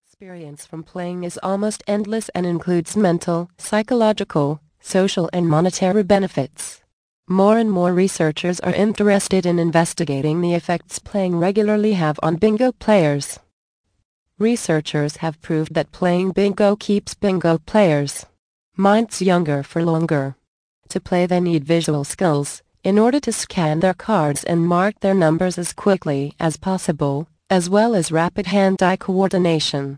Bingo Winning Secrets. Audio Book. Vol. 6 of 7. 57 min.